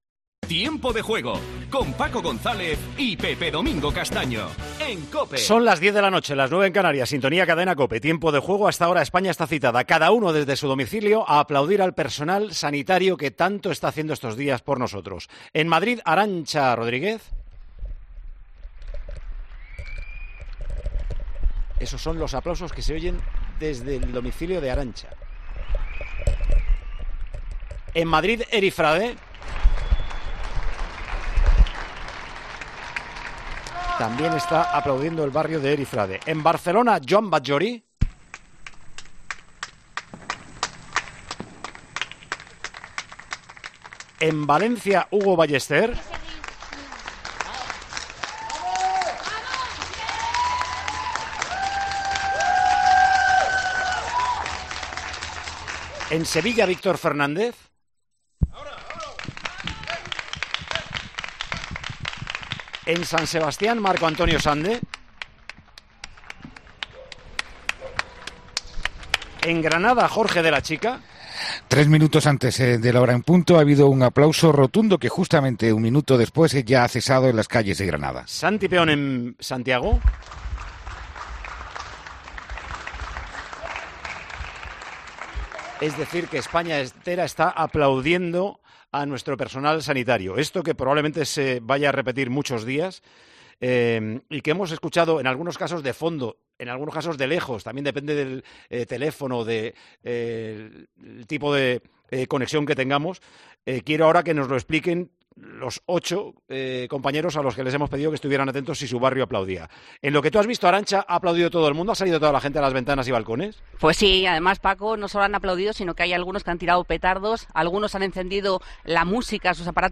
Miles de personas se han asomado este sábado al balcón de sus casas para aplaudir y rendir homenaje a los trabajadores sanitarios que se están dejando la piel estos días en la lucha contra el coronavirus. El director de este programa especial, Paco González, ha acompañado a los oyentes por una ronda de conexiones entre una larga lista de trabajadores y colaboradores del equipo de la radio líder deportiva en España.
El presentador Paco González dirigía como si de orquesta se tratase esta ronda de homenajes a lo largo y ancho de toda España y que hemos podido escuchar a través de Tiempo de Juego.